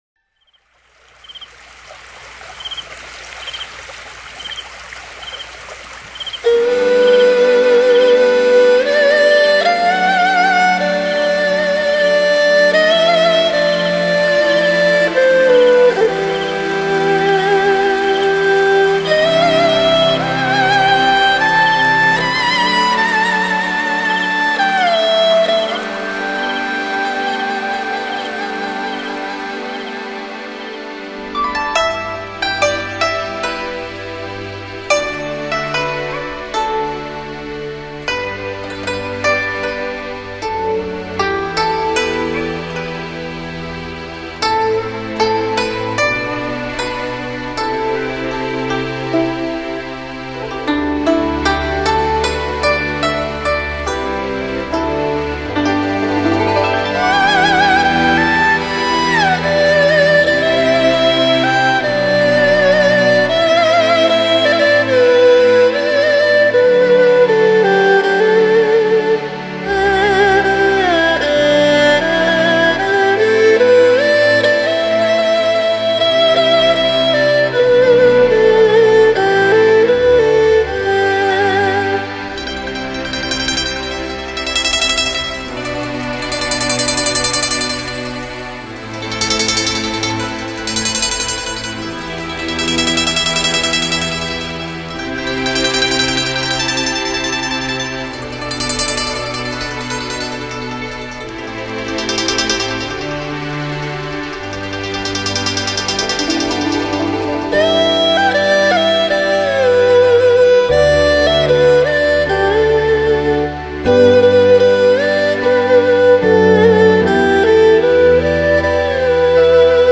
箏是中國傳統彈撥樂器，源於秦而盛於唐。
箏的音色華麗優美，明亮抒情，善於表現行雲流水的意境，常用於獨奏、合奏、重奏器樂和聲樂伴奏中。